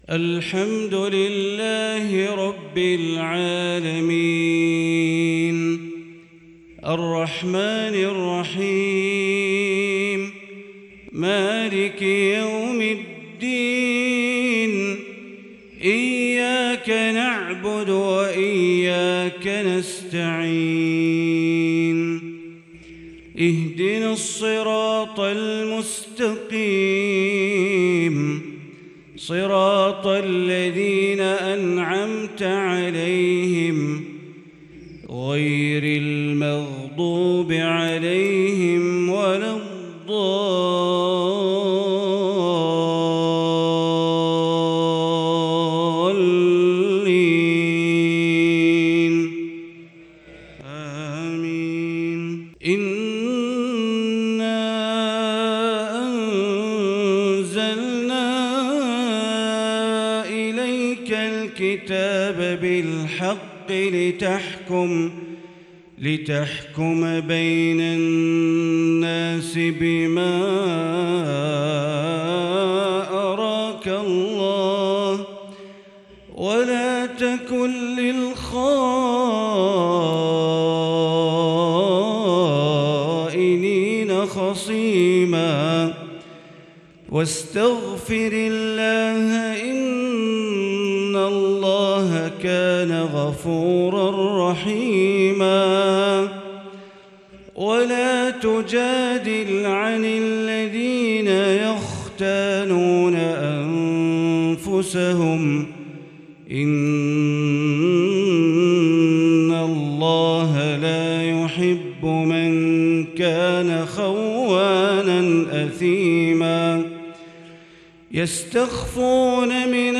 صلاة الظهر الشيخان ياسر الدوسري وعلي الحذيفي